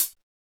Southside Closed Hatz (22).WAV